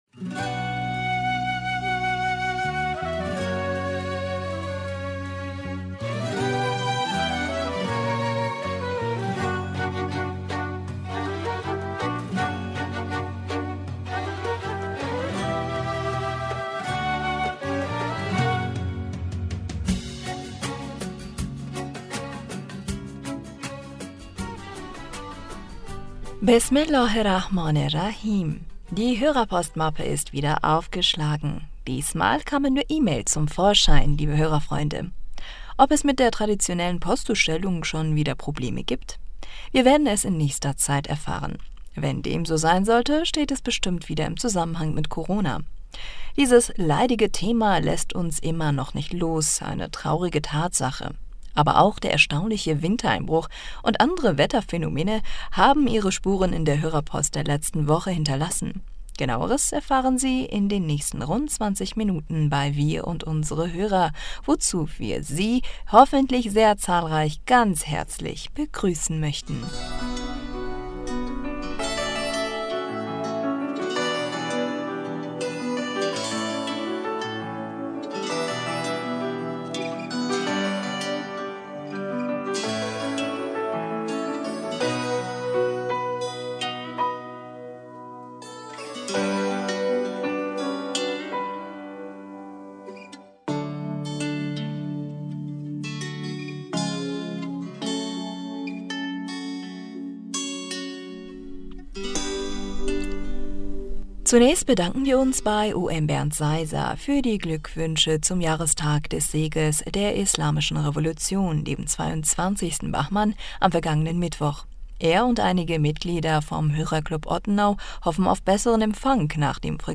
Hörerpostsendung am 14. Februar 2021 Bismillaher rahmaner rahim - Die Hörerpostmappe ist wieder aufgeschlagen, diesmal kamen nur E-Mails zum Vorschein lie...